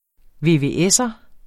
Udtale [ veveˈεsʌ ]